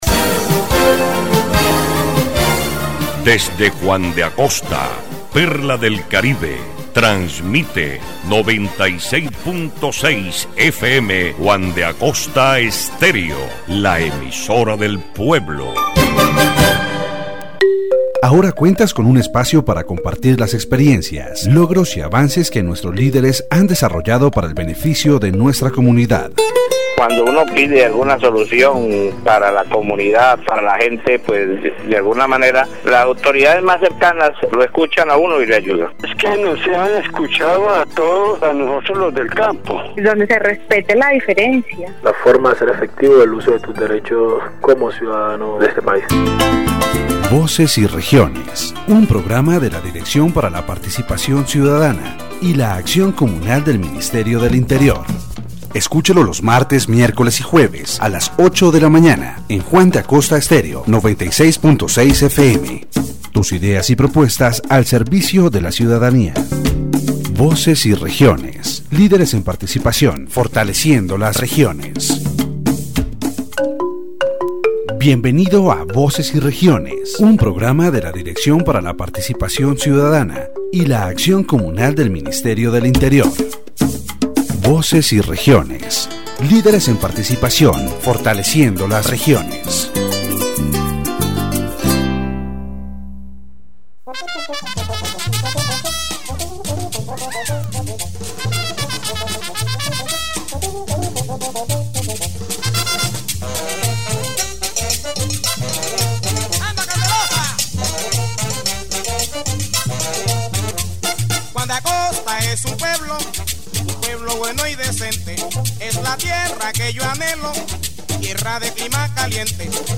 The program Voices and Regions, broadcast on Juan de Acosta Estéreo 96.6 FM, is a platform that promotes citizen participation and strengthens communities by informing them about their rights. This episode focuses on citizen oversight organizations, which are democratic mechanisms that allow citizens and community groups to monitor public administration and report any irregularities in government resource management.